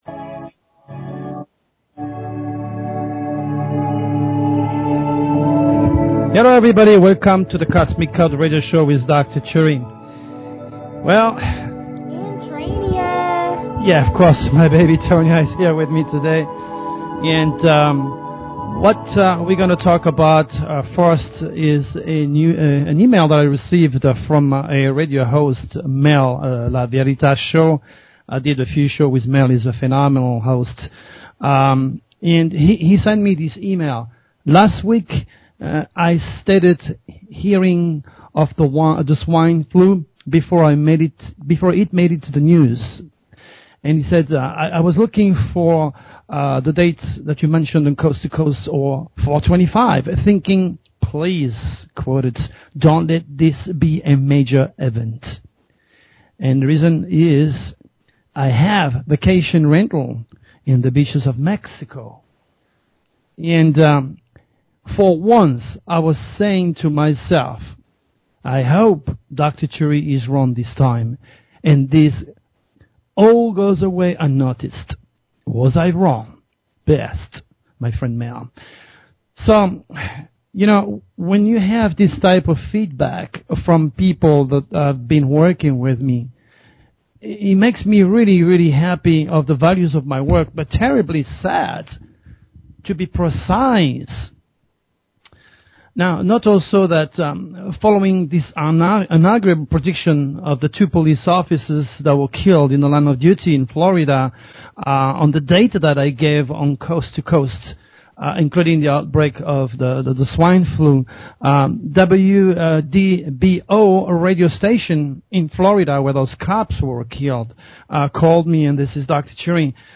Show Headline The_Cosmic_Code Show Sub Headline Courtesy of BBS Radio The Cosmic Code - April 29, 2009 The Cosmic Code Please consider subscribing to this talk show.